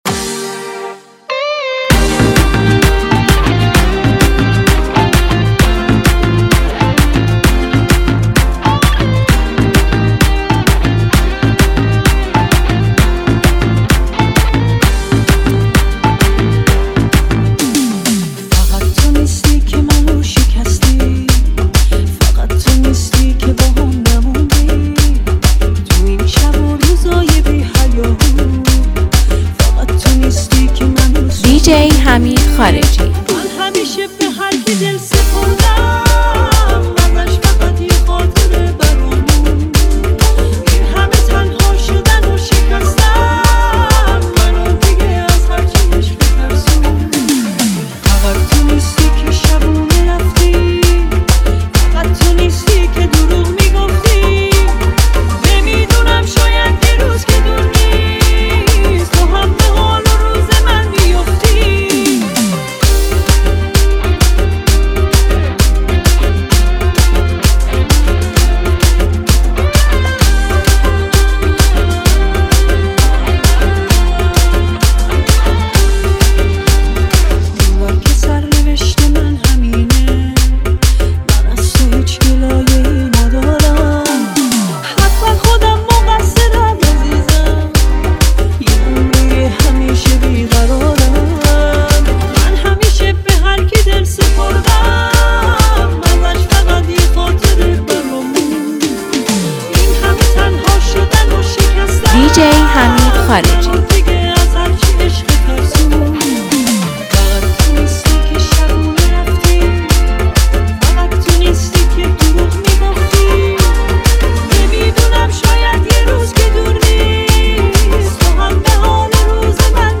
فضایی نوستالژیک و دلنشین را برای شما رقم می زند.